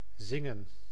Ääntäminen
IPA: [zɪŋ.ǝː]
IPA: /ˈzɪ.ŋə(n)/